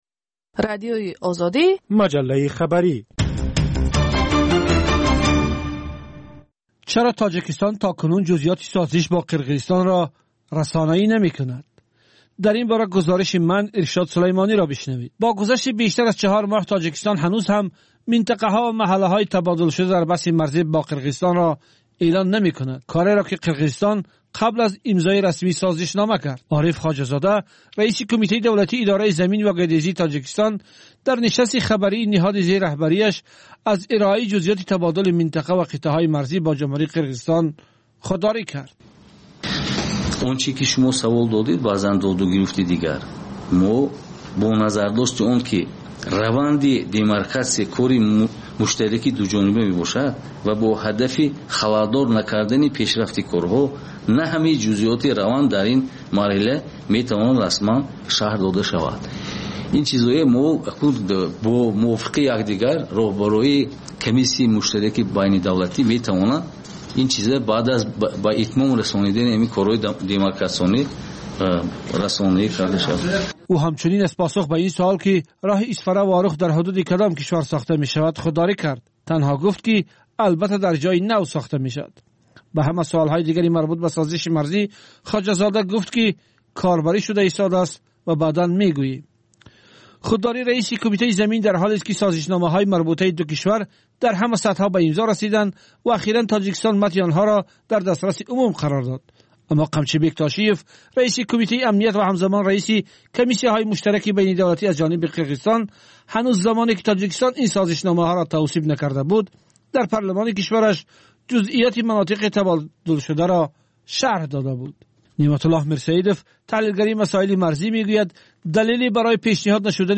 Тозатарин ахбори ҷаҳон, минтақа ва Тоҷикистон, таҳлилу баррасиҳо, мусоҳиба ва гузоришҳо аз масъалаҳои сиёсӣ, иҷтимоӣ, иқтисодӣ, фарҳангӣ ва зистмуҳитии Тоҷикистон.